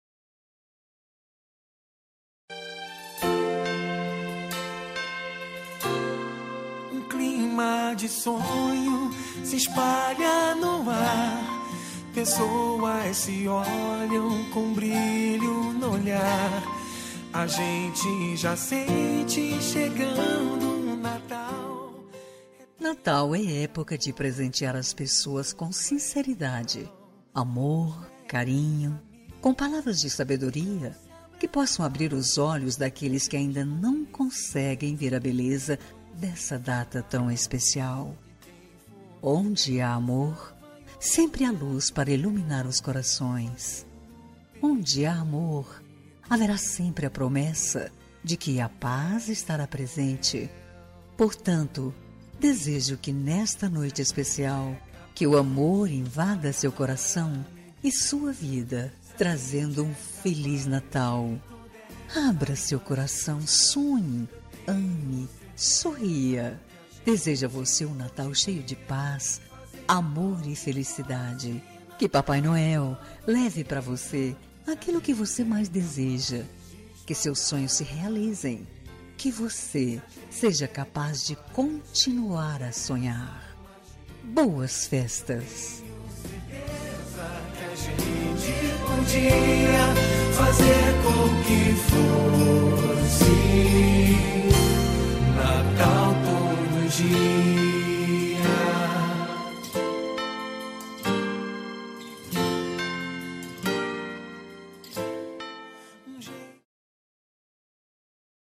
Natal Pessoa Especial – Voz Feminina – Cód: 348961